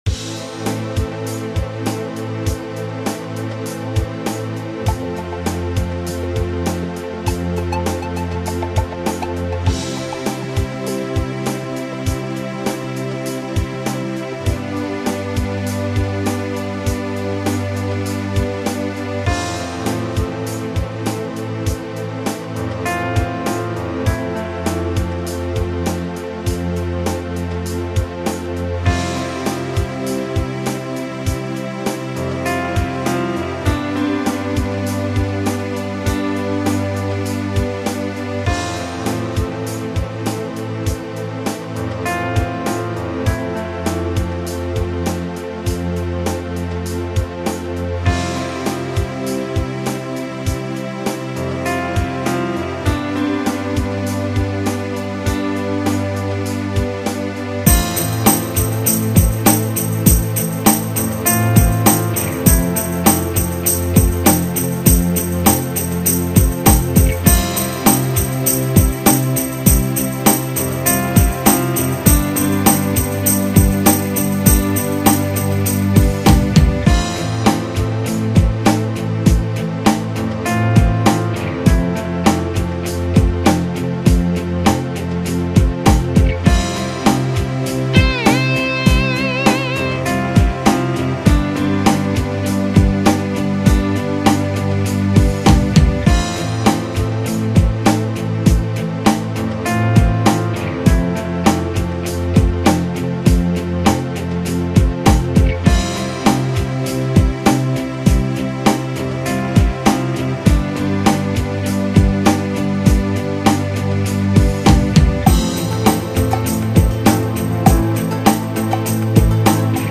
Inspiring